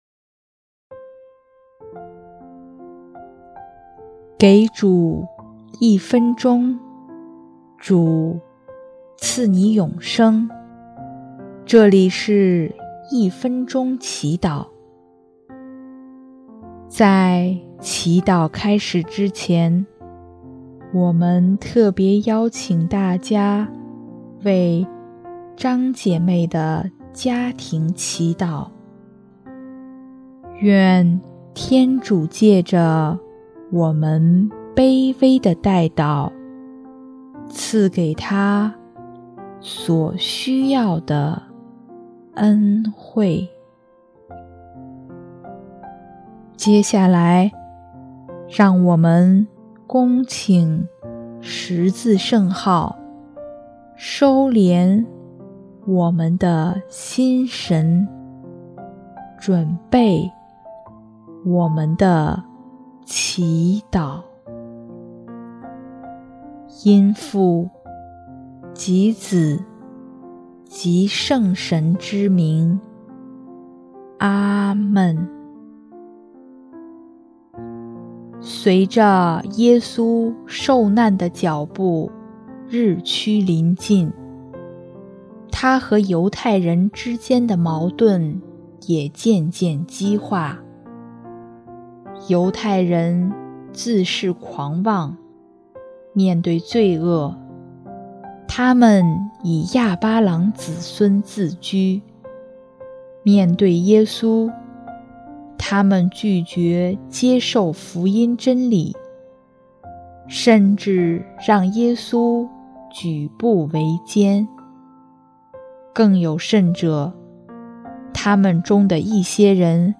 【一分钟祈祷】| 3月30日 让我们接纳被人拒绝的主